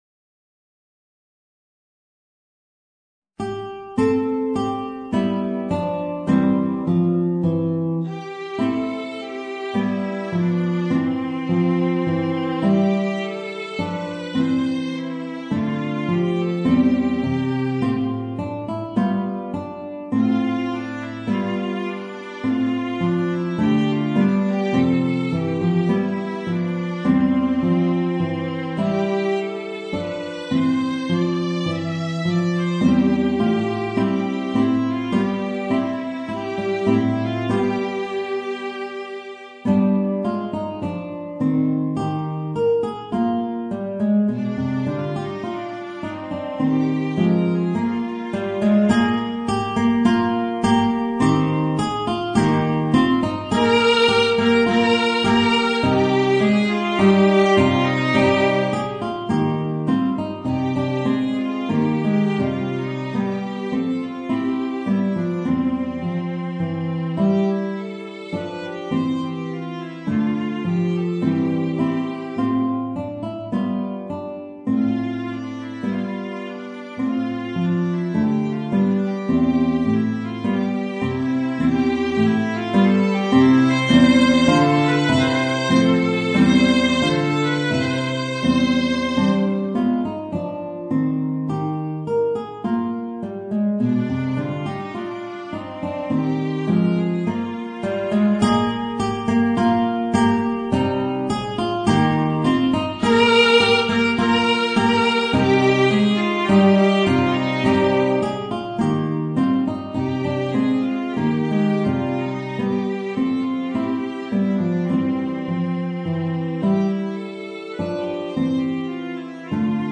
Voicing: Guitar and Viola